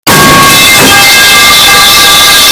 Falling Metal Pipe Sound Effect Free Download
Falling Metal Pipe